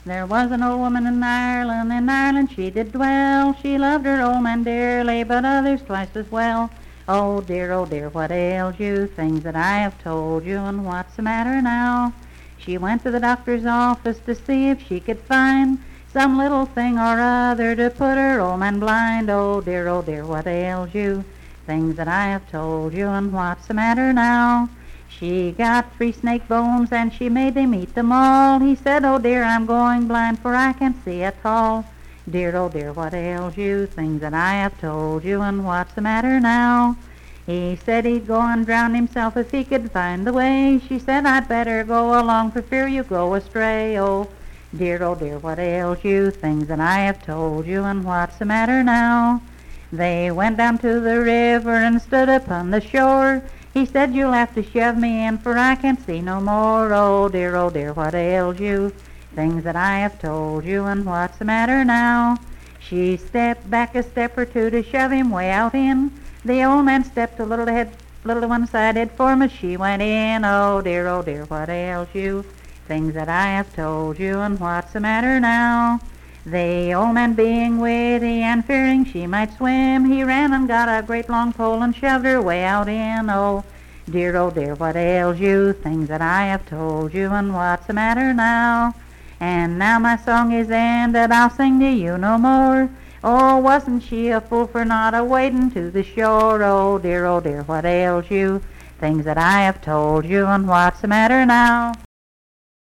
Unaccompanied vocal music performance
Verse-refrain 8d(5w/R).
Voice (sung)